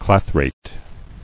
[clath·rate]